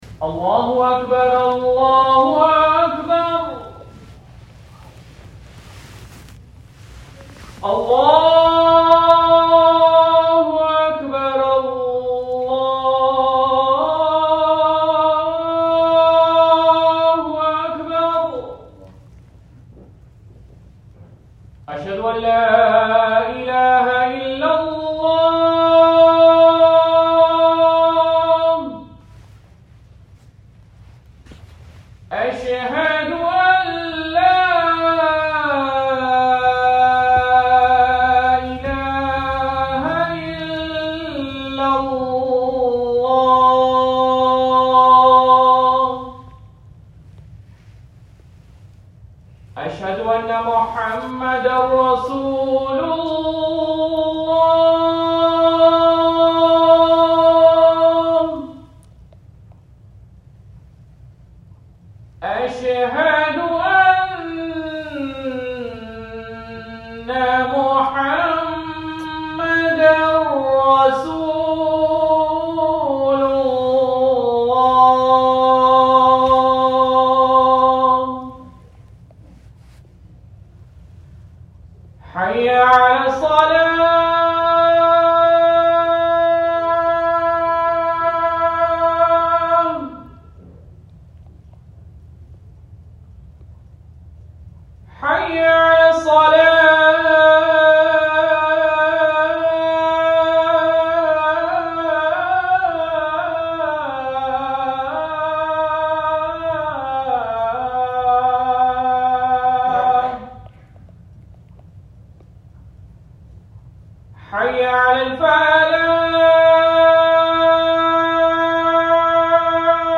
اذان نوری مسجد